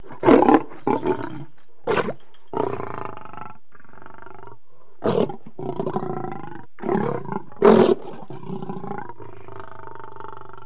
دانلود صدای حیوانات جنگلی 108 از ساعد نیوز با لینک مستقیم و کیفیت بالا
جلوه های صوتی